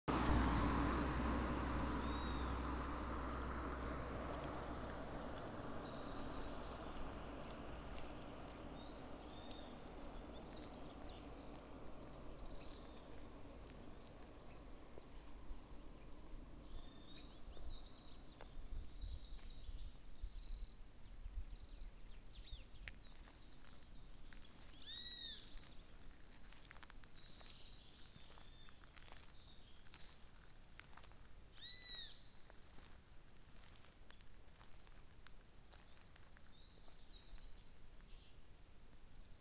Tropical Parula (Setophaga pitiayumi)
Sex: Indistinguishable
Location or protected area: Dique El Cadillal
Condition: Wild
Certainty: Recorded vocal